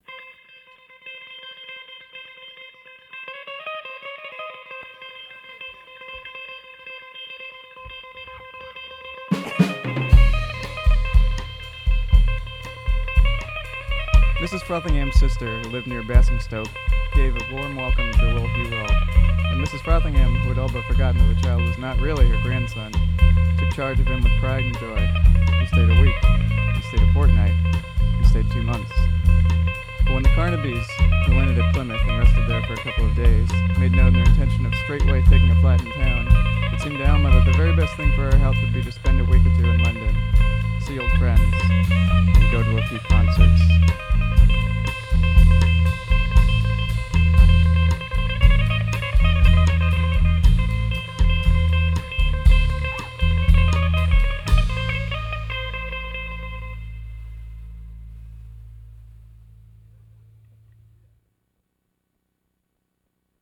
trombone, guitar, vocals, keyboards, aux percussion
bass, vocals, probably aux percussion